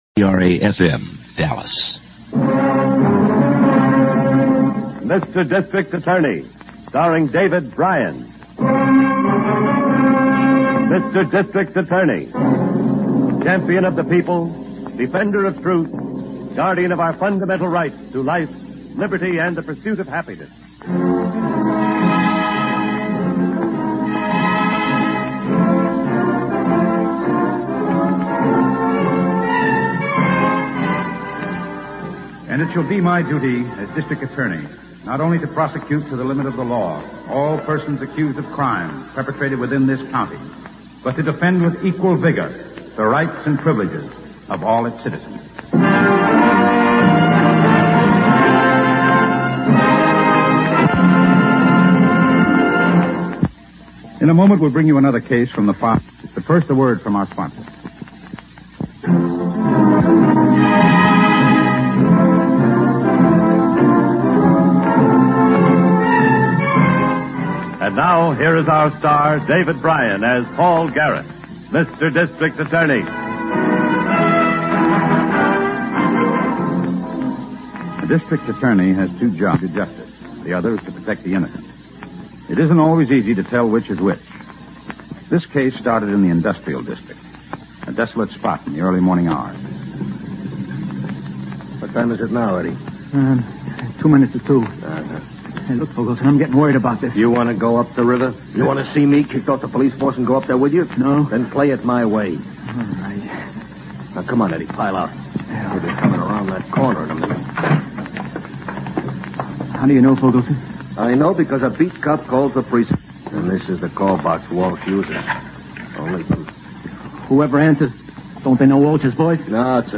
District Attorney is a radio crime drama, produced by Samuel Bischoff, which aired on NBC and ABC from April 3, 1939, to June 13, 1952 (and in transcribed syndication through 1953).